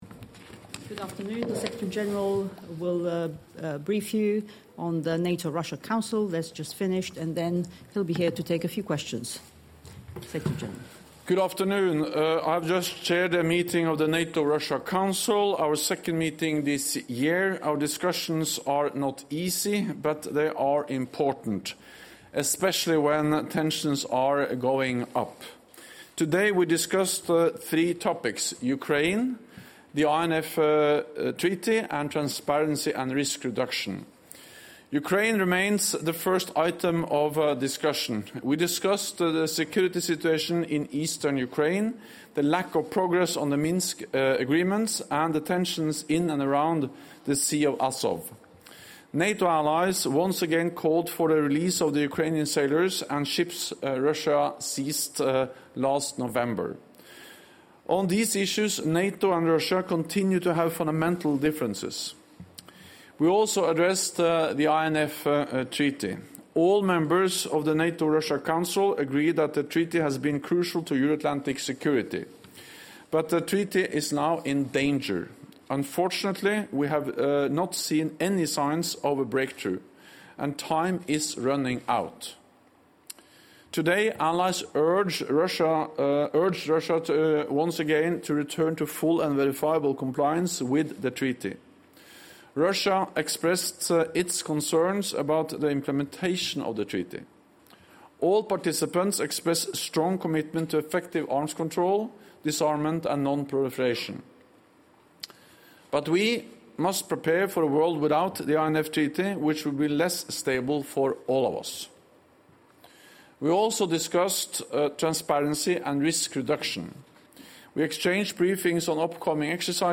Пресс-конференция Генерального секретаря НАТО Йенса Столтенберга после заседания Совета Россия–НАТО